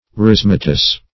Search Result for " rhizomatous" : Wordnet 3.0 ADJECTIVE (1) 1. producing or possessing or resembling rhizomes ; The Collaborative International Dictionary of English v.0.48: Rhizomatous \Rhi*zo"ma*tous\, a. (Bot.)